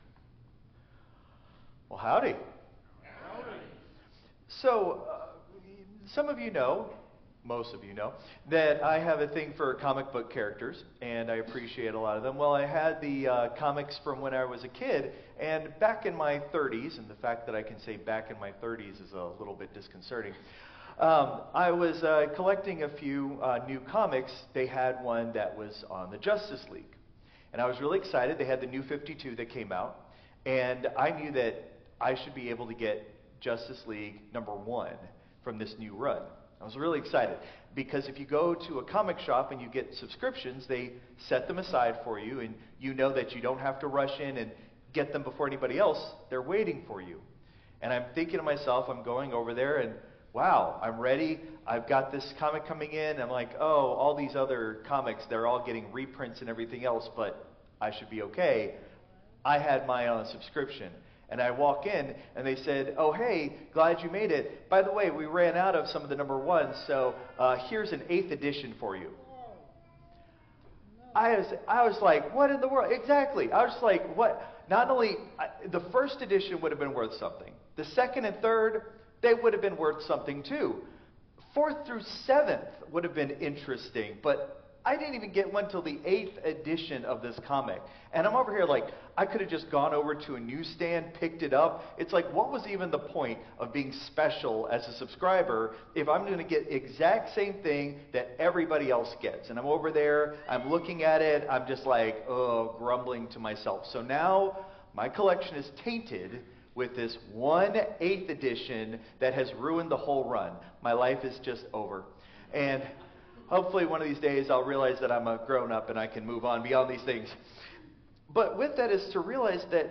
Christ Memorial Lutheran Church - Houston TX - CMLC 2025-02-23 Sermon (Contemporary)